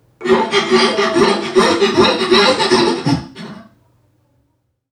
NPC_Creatures_Vocalisations_Robothead [90].wav